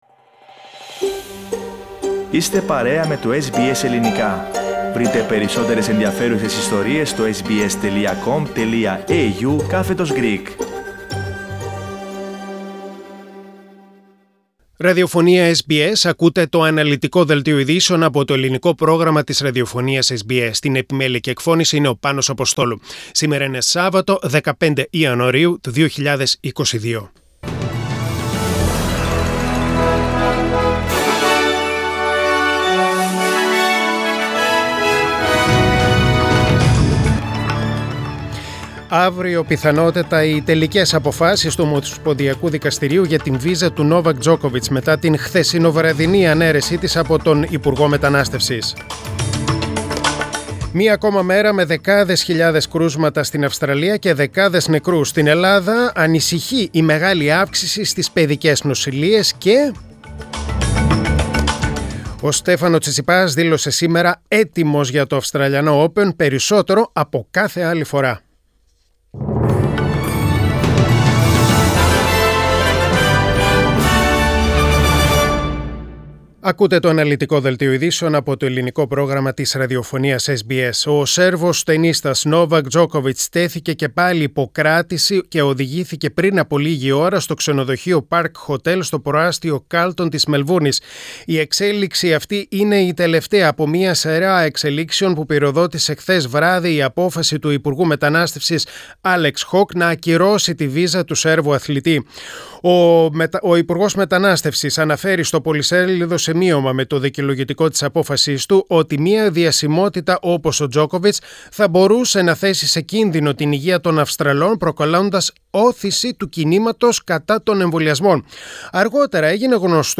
News in Greek: Saturday 15.1.2022